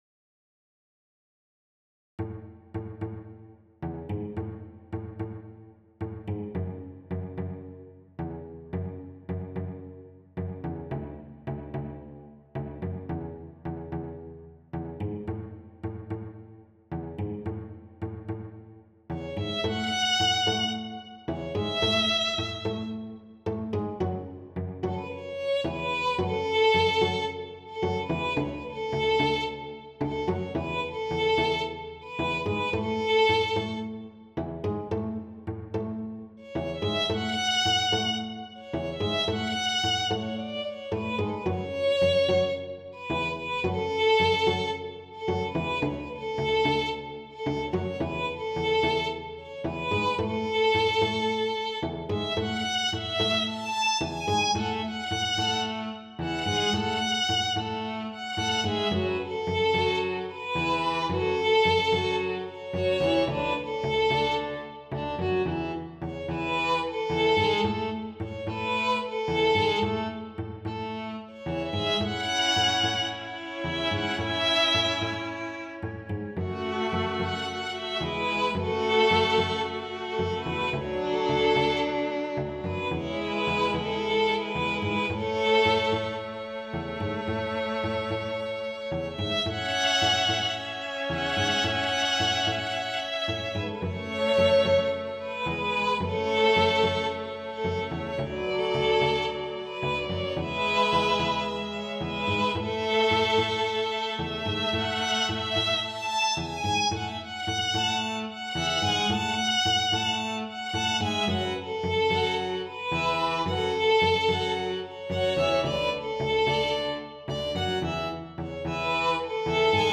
● Violino I
● Violino II
● Viola
● Violoncelo